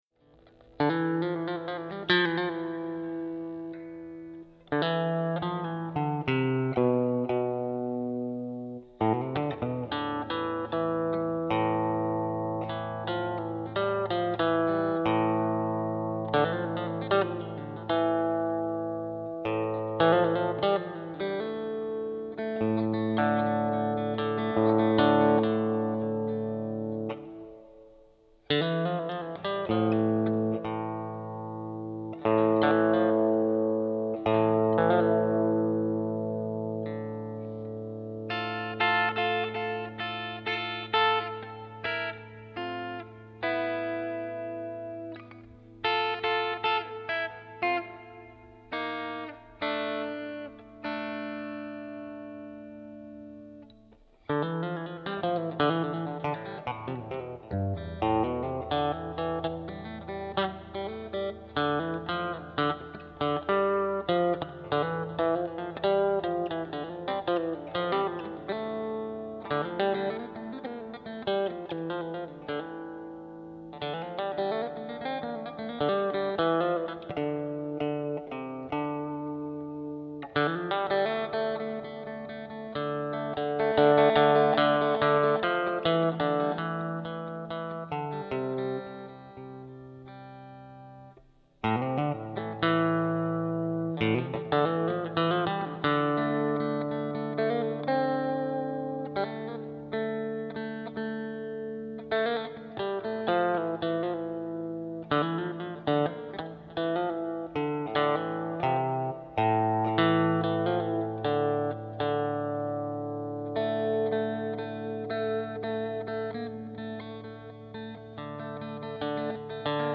Composé en Février 2010 sur Sonar et Guitare Godin.
Les parties piano et voix en chantier.